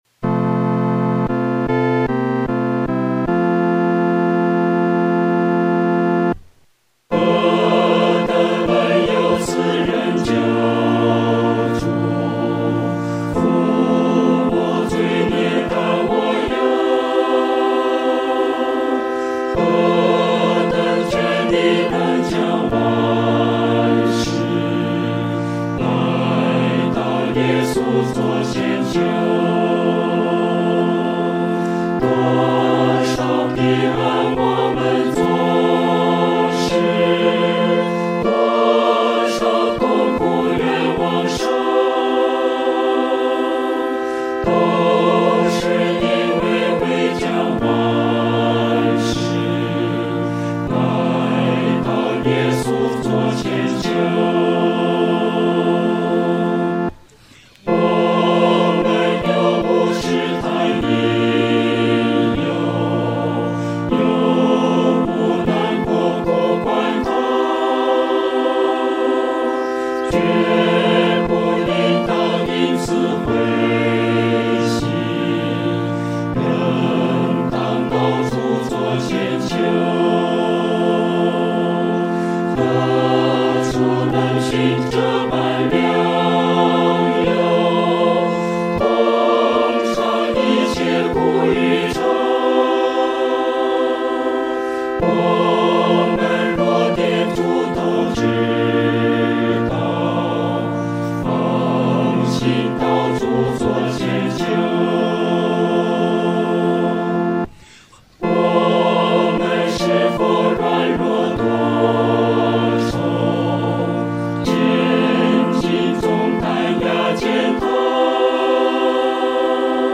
合唱 （全首）
四声(A)